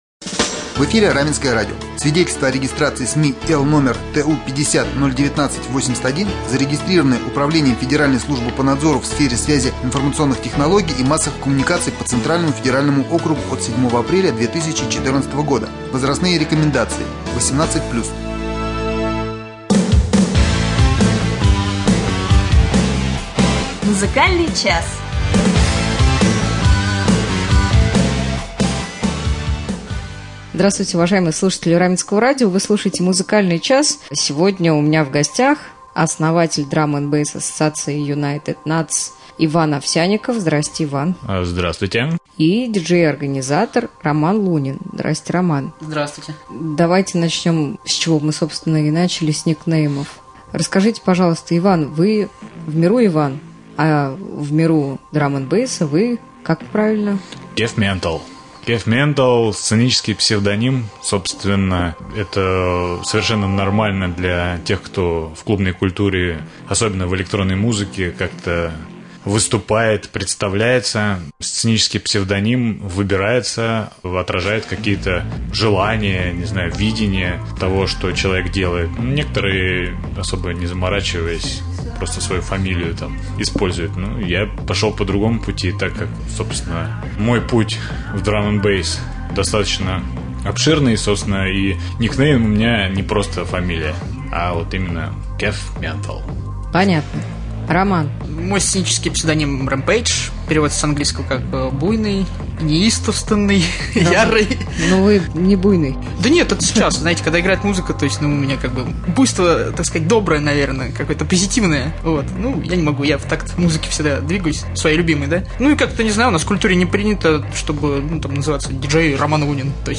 В нашем предновогоднем выпуске «Музыкального часа» гитары не будет. А будет знакомство с такой культурой, как Drum and bass.